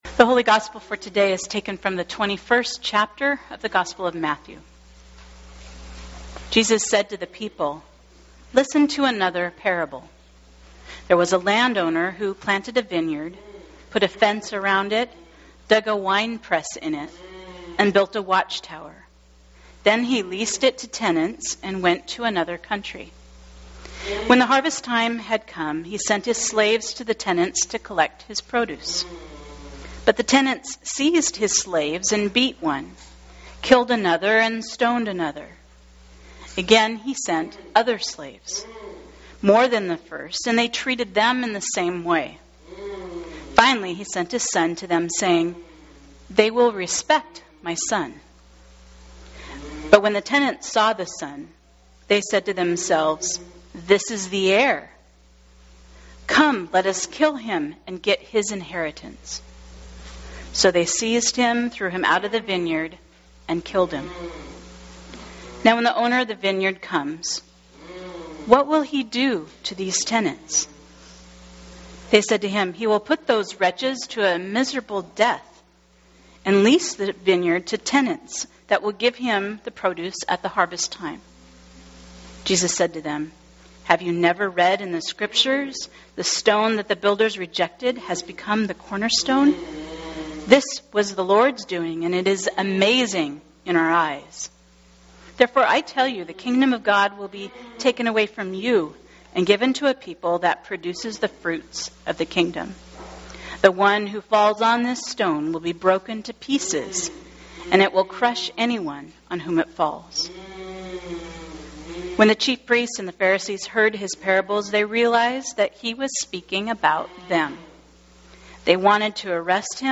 Today’s sermon challenges us, similarly, to think about what kinds of fruits we are producing in our own vineyards…in our hearts, in our homes, in our communities.